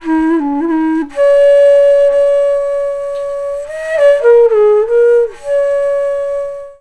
FLUTE-B05 -L.wav